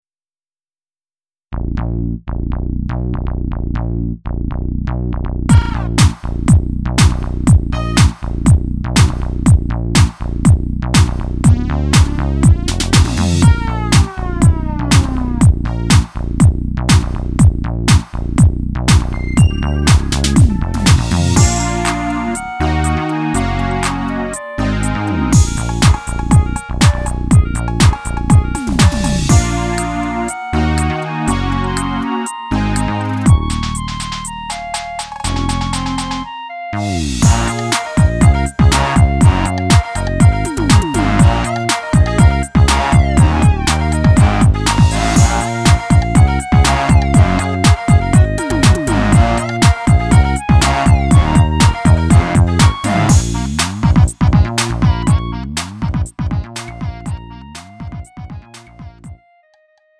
음정 -1키 2:50
장르 가요 구분